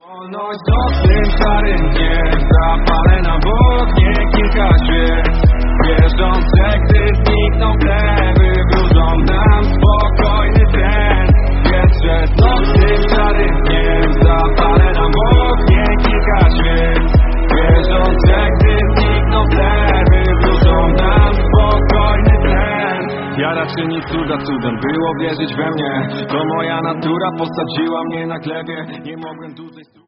Hip-Hop/Rap